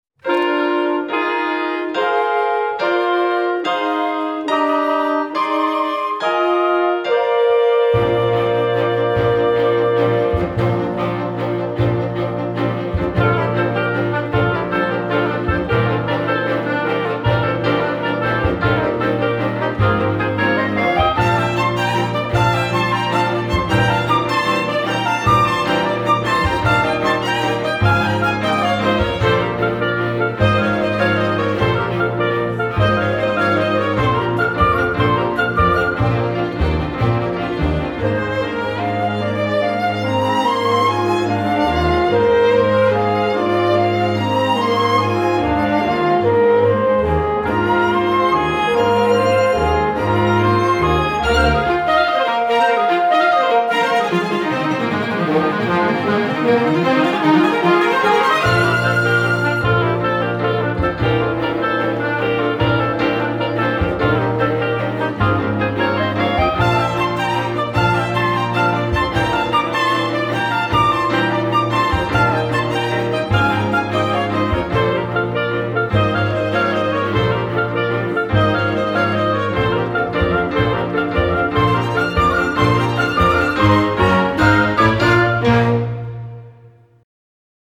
A brief but jaunty ride over fields of mixed meters.
Instrumentation: 1111-1000, pno, str